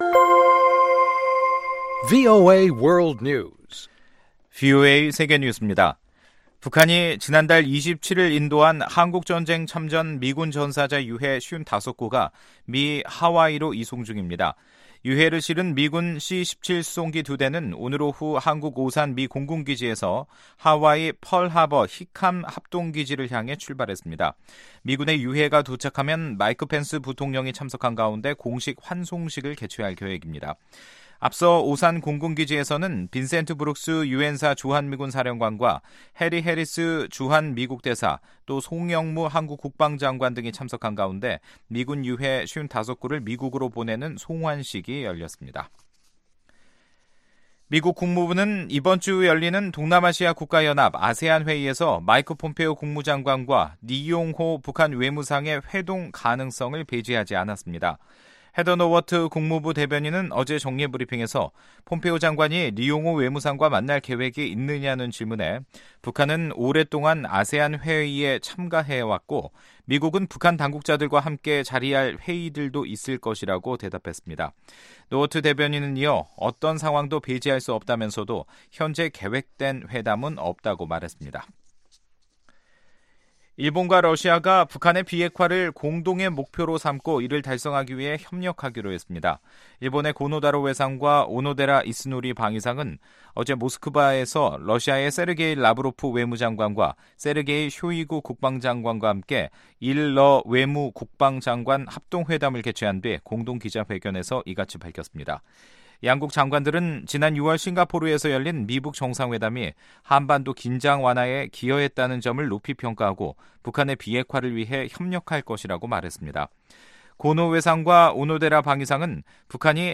세계 뉴스와 함께 미국의 모든 것을 소개하는 '생방송 여기는 워싱턴입니다', 2018년 8월 1일 저녁 방송입니다. ‘지구촌 오늘’에서는 미국이 2천억 달러 중국산 수입품에 새로 매기는 관세를 25%로 높일 것으로 알려졌다는 소식, ‘아메리카 나우’ 에서는 연방 법원이 3D 프린터를 이용한 총기 제작에 필요한 도면을 공개하는 것에 제동을 걸었다는 이야기 전해드립니다. '타박타박 미국 여행'에서는 순박한 산 사람들의 터전, 웨스트버지니아를 찾아갑니다.